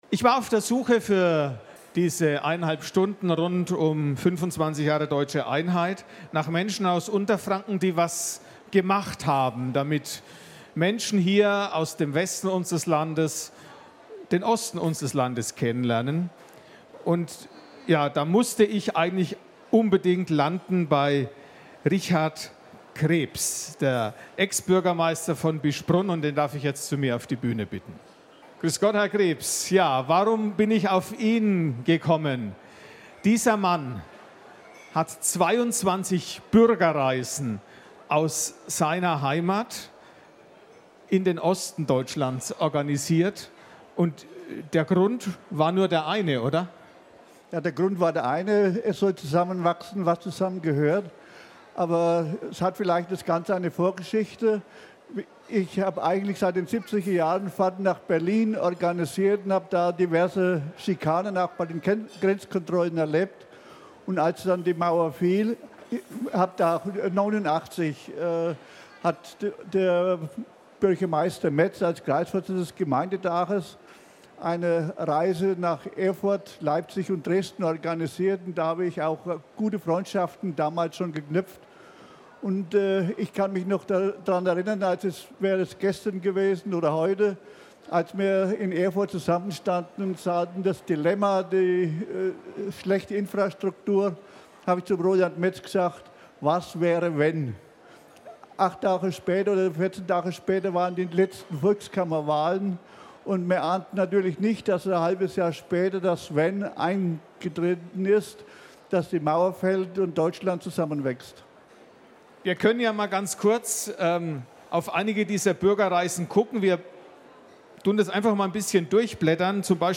Bayerischer Rundfunk - Interview
auf der Mainfrankenmesse